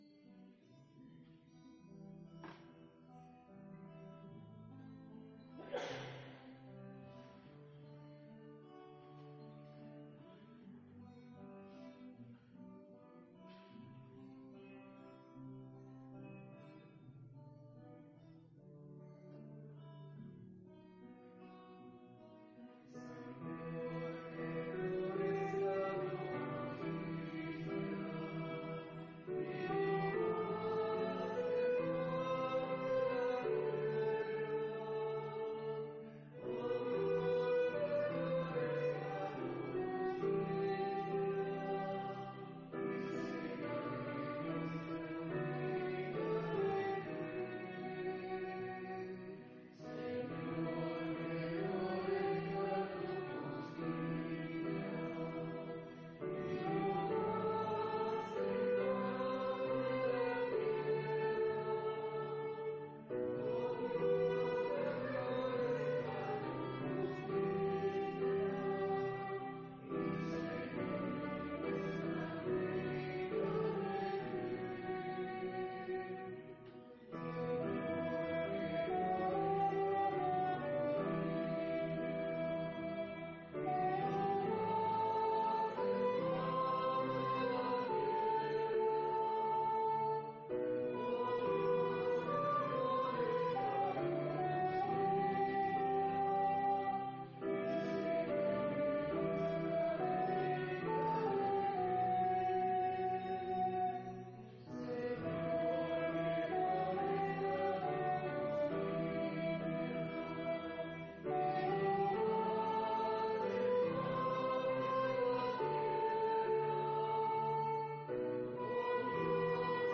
Pregària de Taizé a Mataró... des de febrer de 2001
Convent de la Immaculada - Carmelites - Diumenge 18 de desembre 2016
Vàrem cantar...